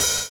DISCO 10 OH.wav